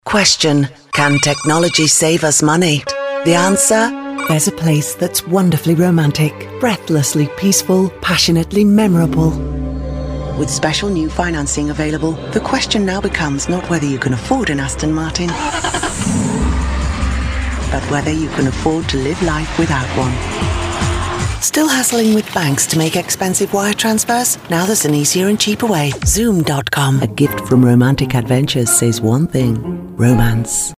Experienced Female British Voice Over, Fast Turnaround, Professional Service
Sprechprobe: Werbung (Muttersprache):